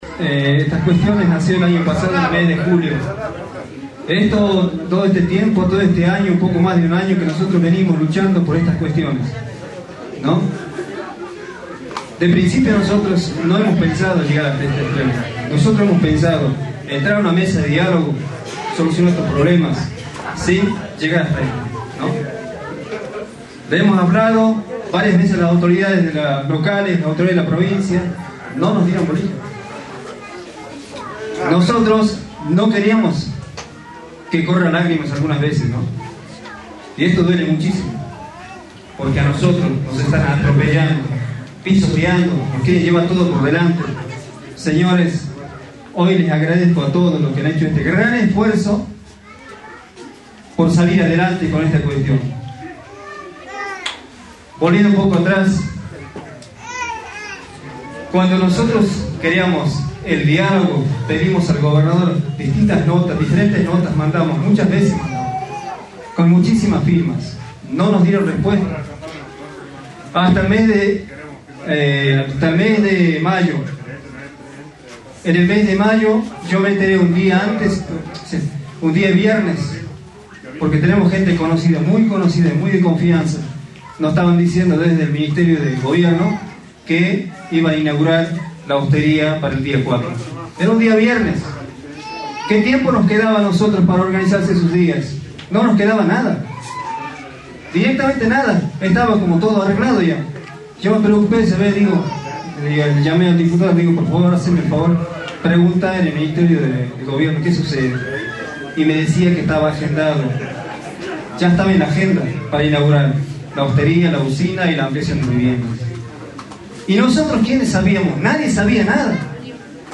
Fue durante un emotivo acto realizado en el local de la Organización de Comunidades Aborígenes de Nazareno (OCAN), donde los presentes agradecieron a la madre tierra tras el rechazo en la consulta popular de los pobladores del Municipio de Nazareno al proyecto turístico del gobernador Juan Manuel Urtubey, establecido durante el gobierno de su antecesor Juan Carlos Romero.